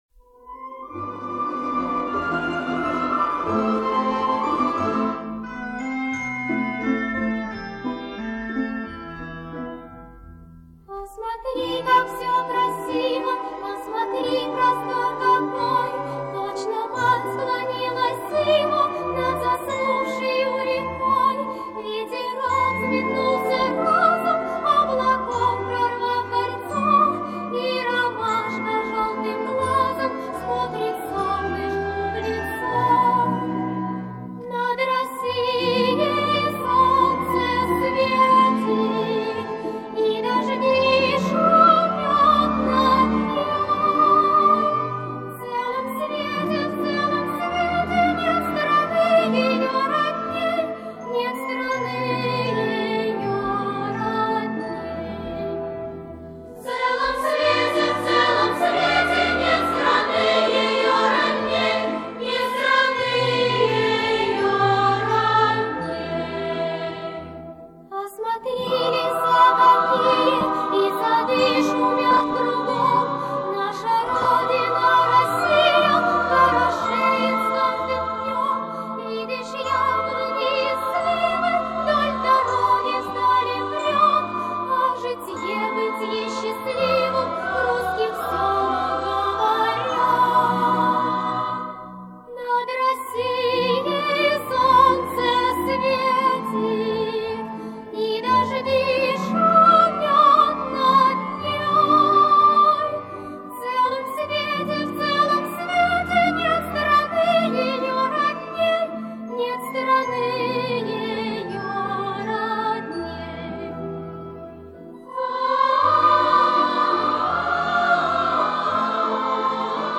Лирическая пионерская песня о красоте Советской Родины